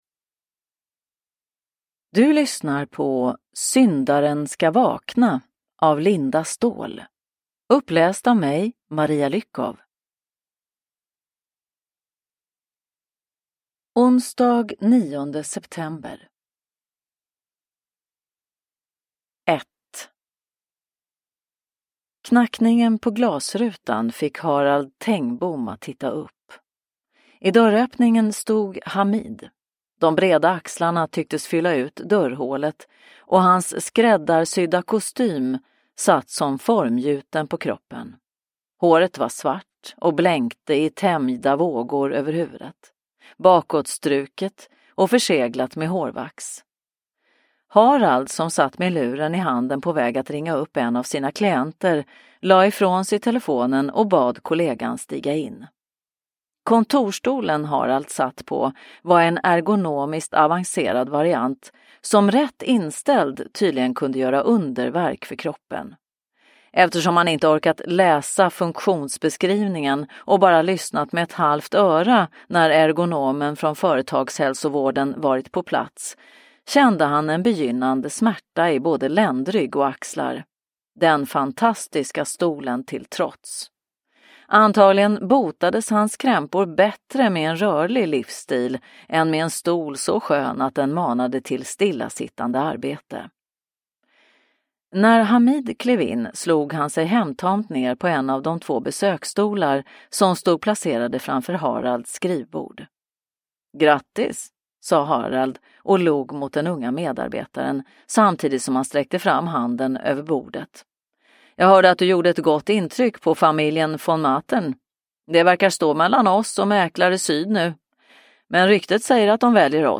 Syndaren ska vakna – Ljudbok – Laddas ner